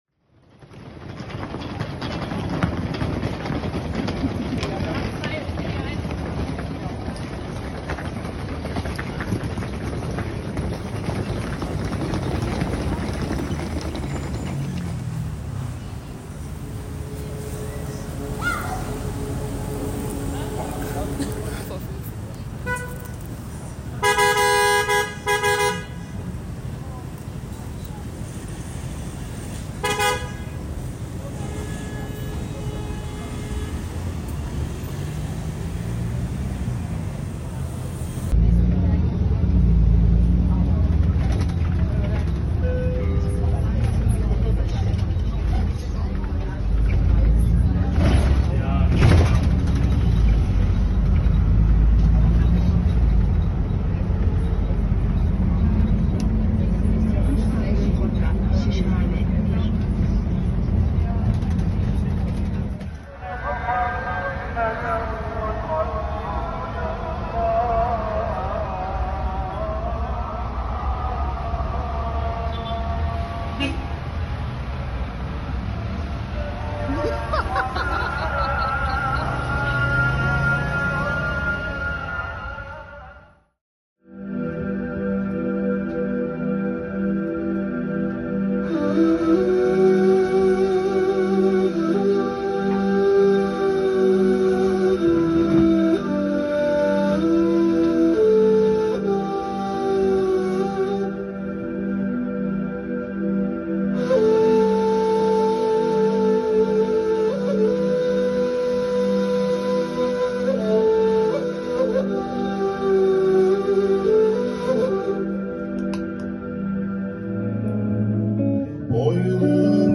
Ich begann, unterwegs mit Handyaufnahmen ein paar dieser Dinge spontan einzufangen (der erste, selektive Schritt der Konstruktion meines „akustischen Istanbuls“): Es sind Aufnahmen von Muezzin-Rufen dabei, von (türkischer) Straßen- und Barmusik, von Gesängen im armenisch-orthodoxen Gottesdienst, Verkehrsgeräuschen, Menschenstimmen, der Müllabfuhr, Katzengejaule und Hundegebell...
Die entstandene Soundcollage ist somit eine bruchstückhafte, stichwortartige Klang-Notiz meiner Erfahrung der Tage in Istanbul, die keinerlei Anspruch auf Vollständigkeit erhebt und zugleich unbegrenzt ist in den Wahrnehmungsmöglichkeiten bei jedem erneuten Hören.
Soundcollage_Istanbul_Januar_2025.mp3